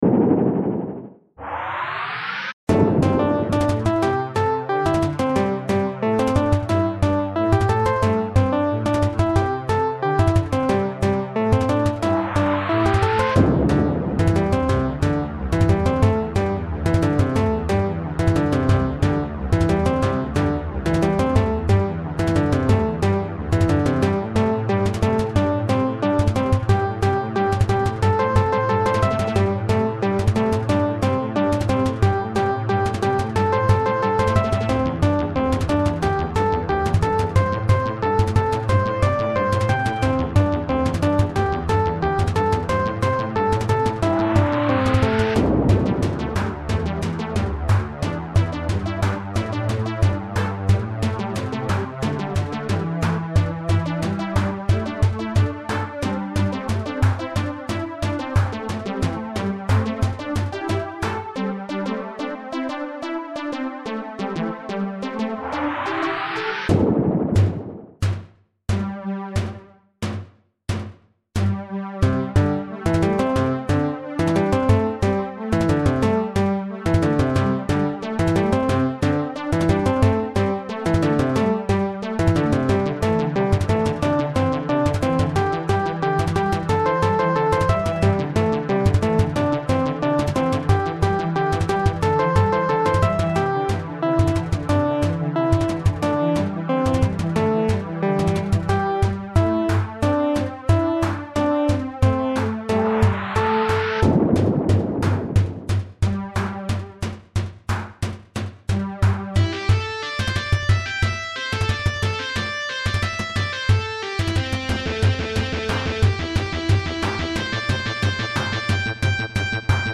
More intense, with some eastern(?) leitmotifs. 23/10/2024